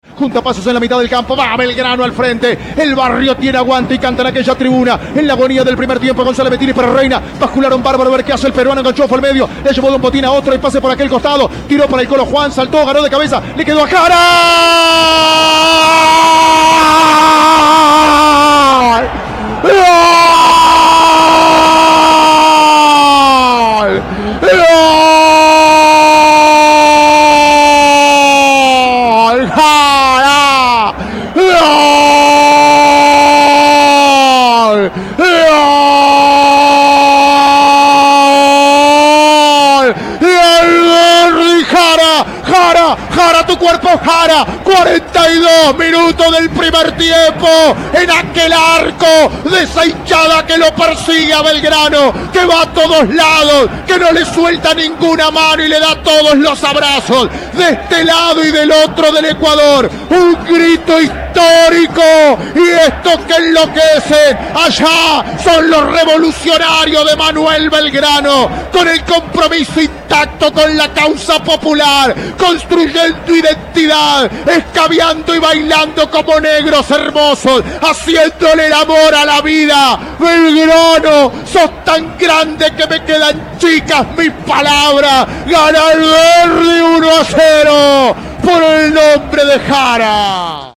Transmisión de Cadena 3